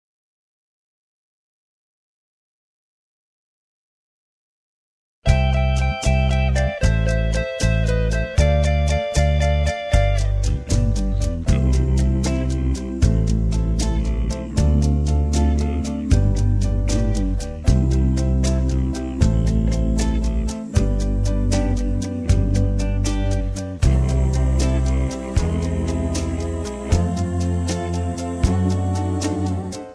karaoke
rock and roll